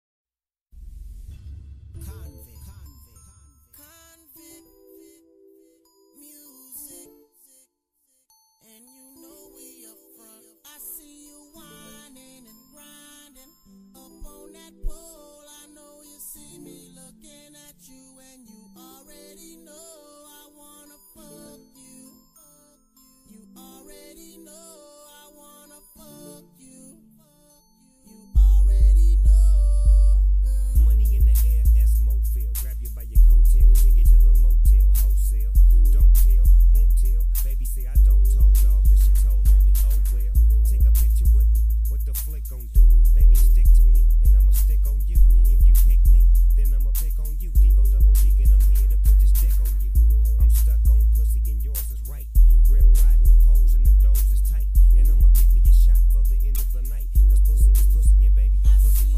Bass Boosted